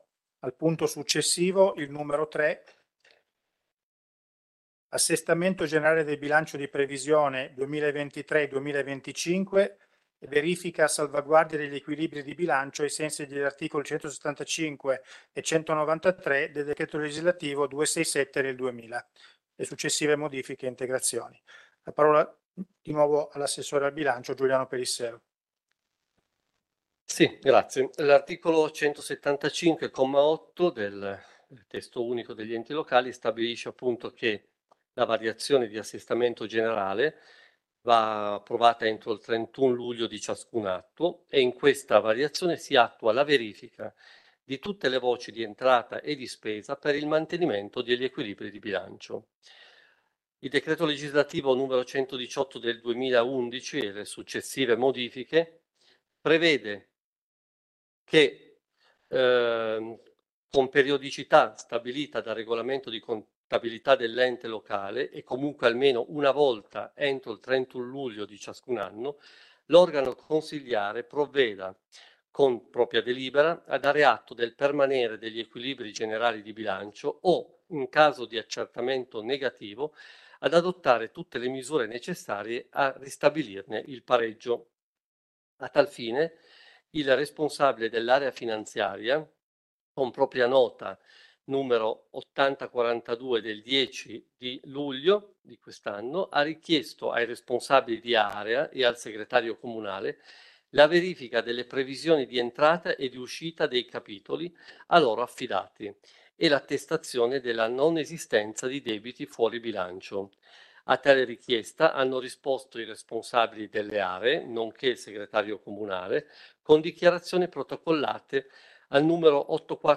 Download of consiglio comunale comune susa 27.07.2023 punto 3 assestamento